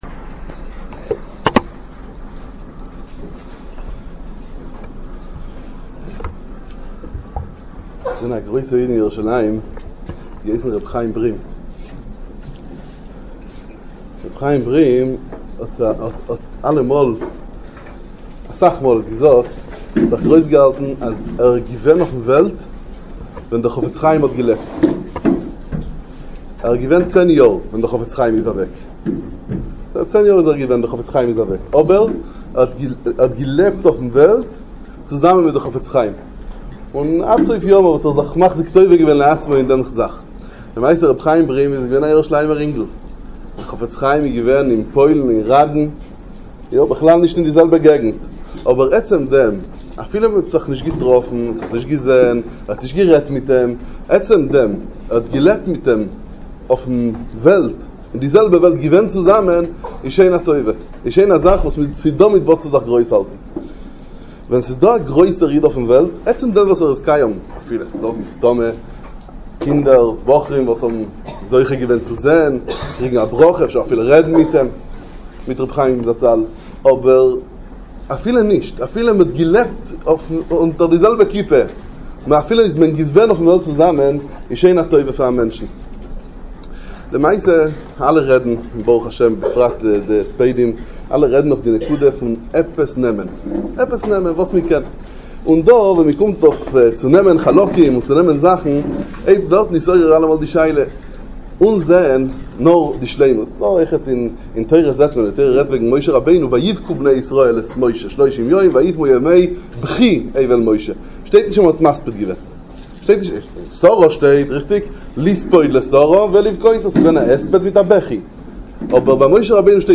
הספד לילדים על מרן הגר"ח קניבסקי זצ"ל באידיש